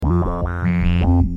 Oberheim - Matrix 1000 12